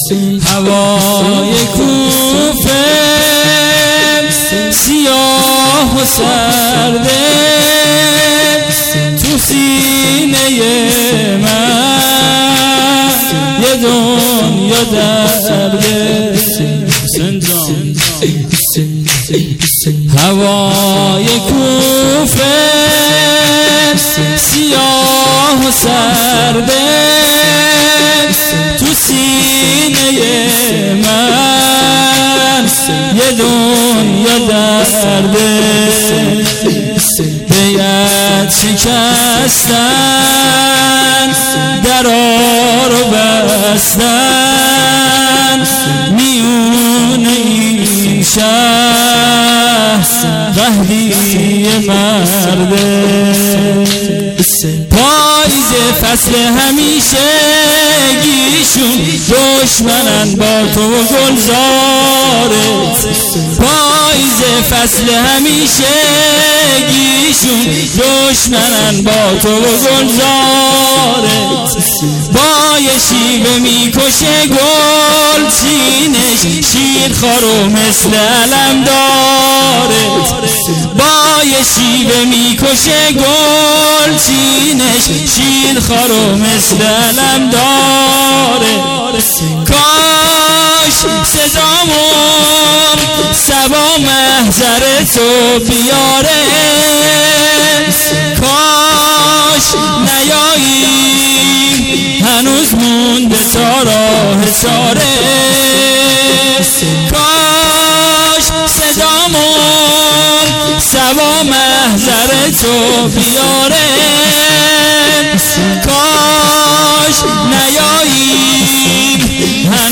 شهادت حضرت مسلم بن عقیل(ع)99